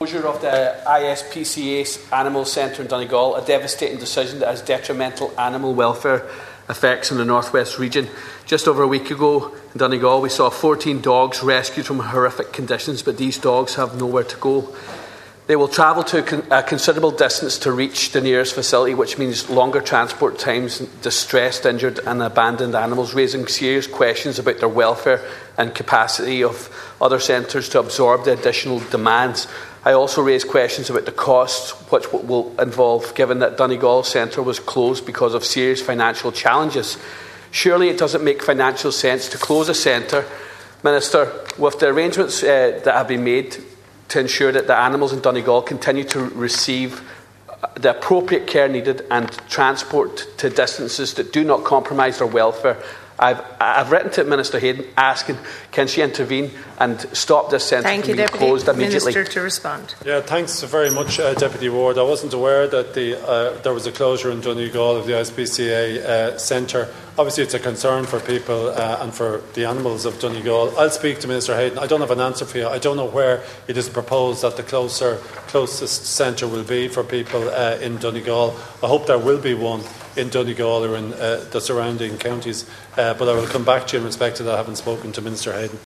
The Dail has heard how the closure of the Donegal ISPCA centre is detrimental to animal welfare in the North West.
In response to Donegal Deputy Charles Ward, Minister Jim O’Callaghan says he hopes a centre would be available in the North West: